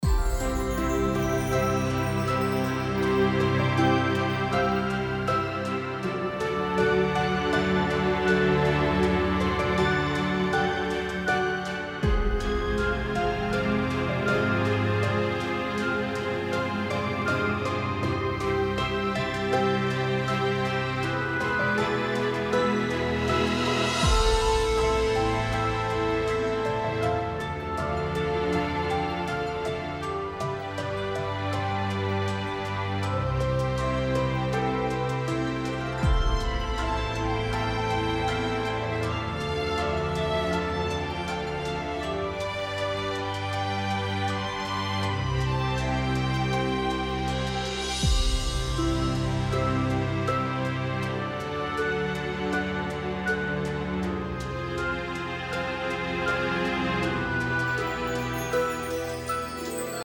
【１. 地圖背景音樂】